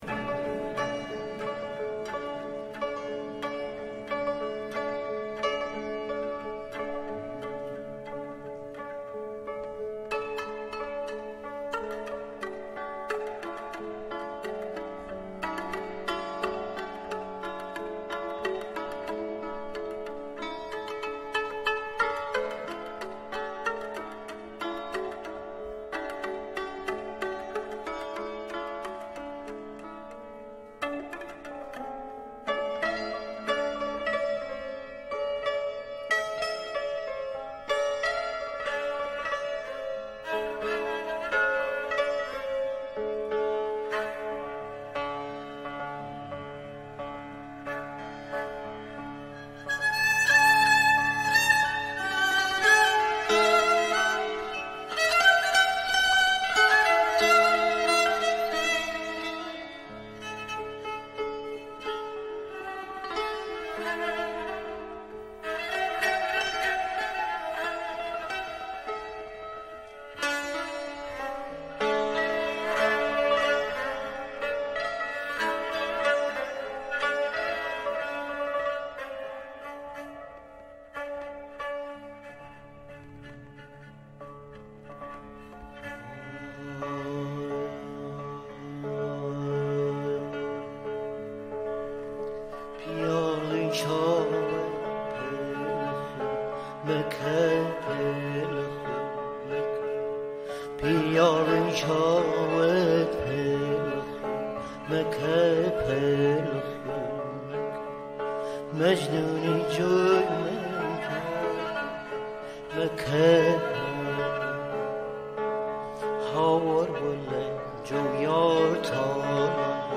تصنیف کردی "'ئامان بە لەنجە و لار" با آواز و کمانچه کیهان کلهر
تصنیف کردی "'ئامان بە لەنجە و لار" با آواز و کمانچه کیهان کلهر سنتور